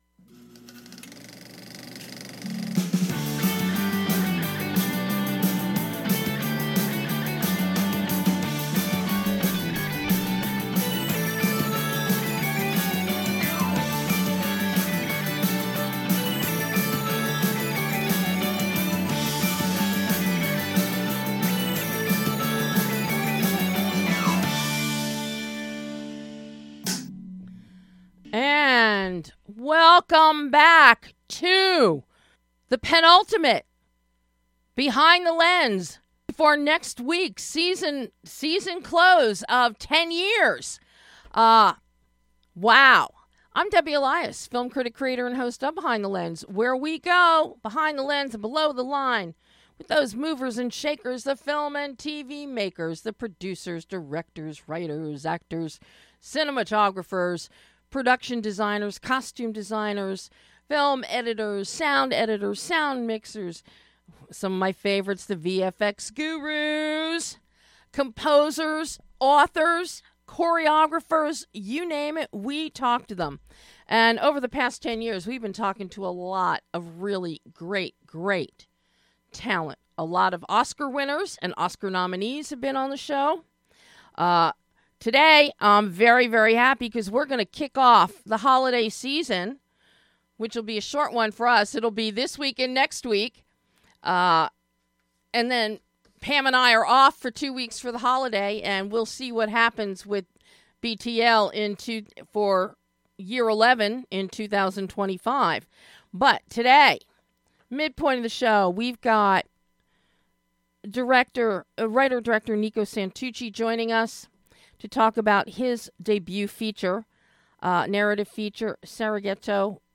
And both gentlemen are live!